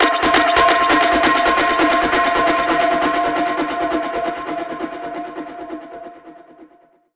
Index of /musicradar/dub-percussion-samples/134bpm
DPFX_PercHit_E_134-11.wav